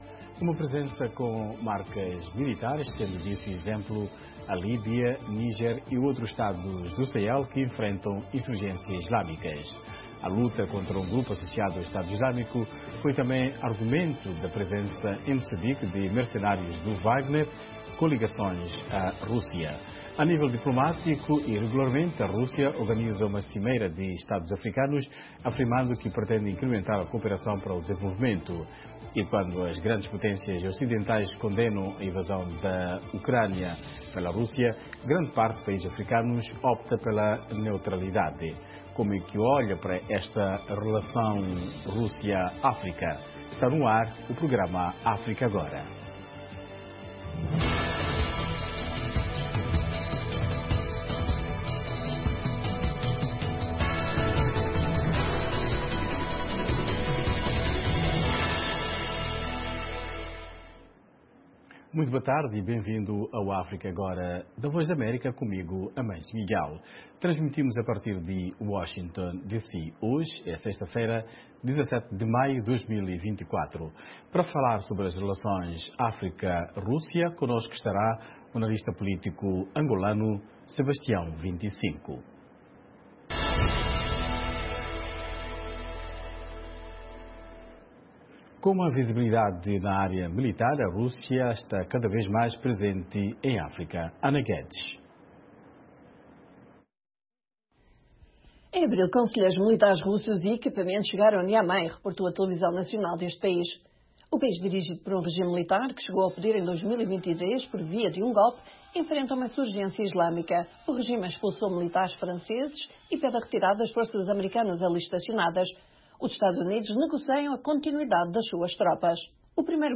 África Agora, o espaço que dá voz às suas preocupaçōes. Especialistas convidados da VOA irão comentar... com a moderação da Voz da América. Um debate sobre temas actuais da África Lusófona.